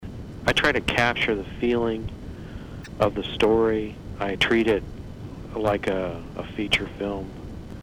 It wouldn't be one of my interviews without some clips from the conversation.